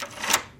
门 " 门锁转03
描述：这是一个前门的锁被转动的声音。
这个文件已经被规范化，并且去除了大部分的背景噪音。
标签： 开锁
声道立体声